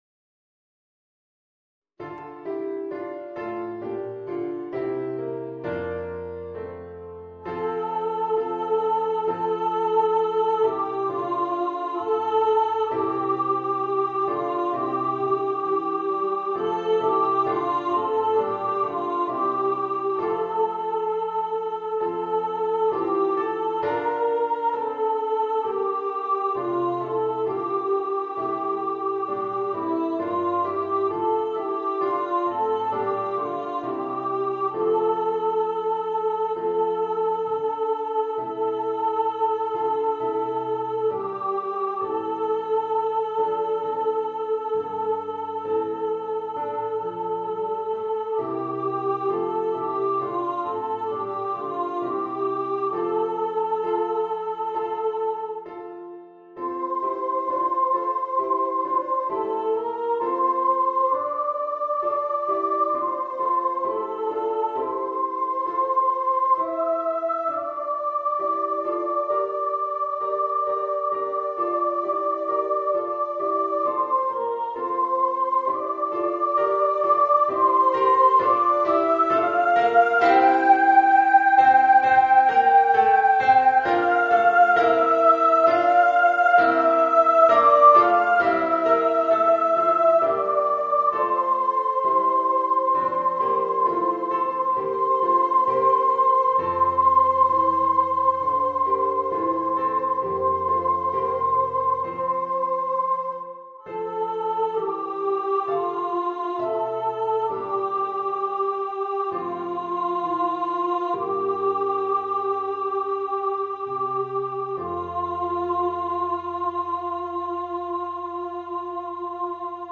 Voicing: Voice and Piano